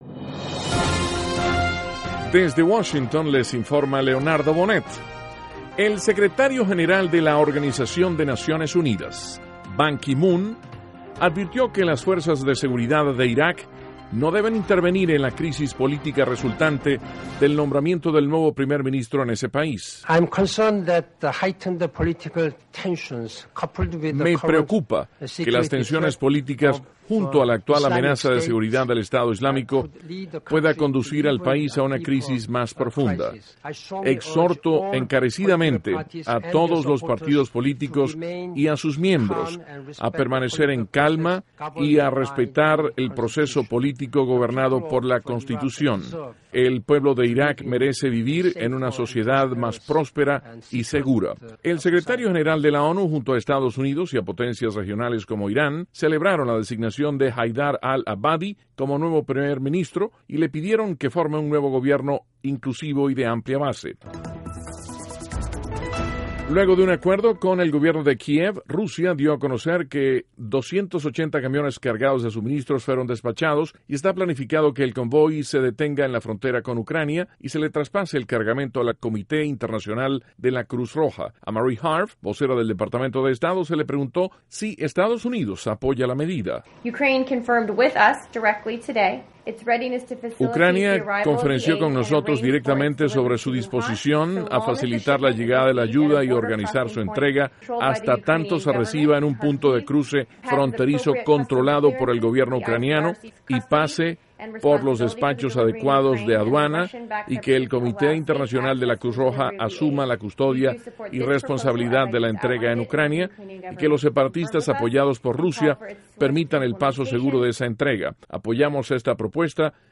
NOTICIAS - 12 DE AGOSTO, 2014